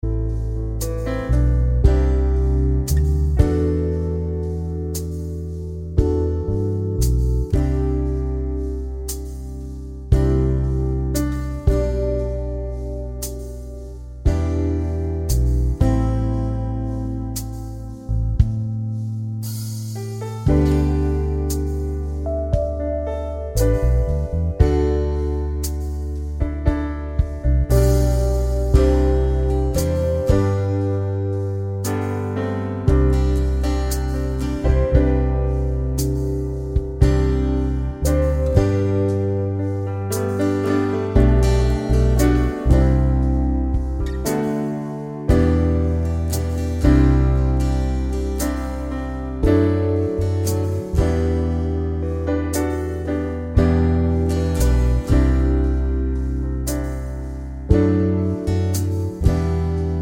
Remastered with No Backing Vocals Pop (1990s) 6:34 Buy £1.50